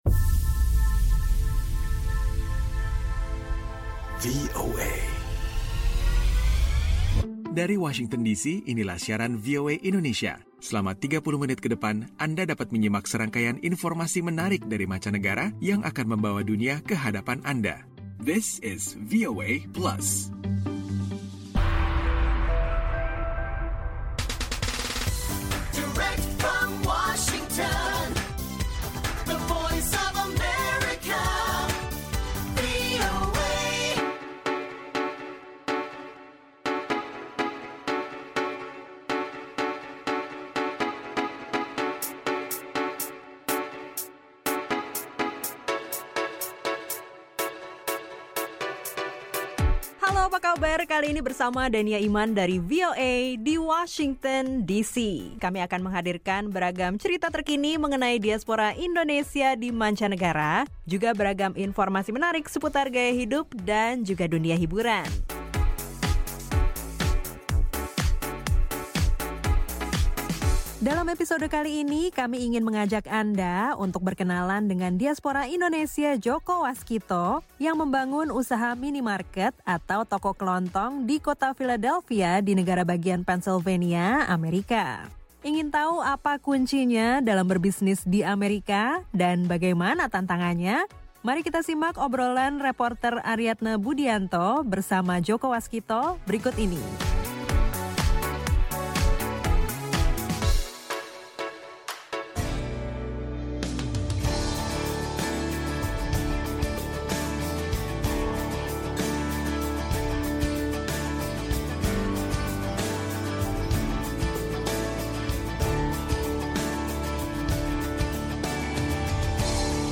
VOA Plus kali ini mengajak anda menyimak obrolan bersama seorang diaspora Indonesia pemilik beberapa bisnis toko kelontong di kota Philadelphia, negara bagian Pennsylvania di AS. Ada pula info tentang para pemeran pengganti pengemudi perempuan dalam dunia perfileman Hollywood.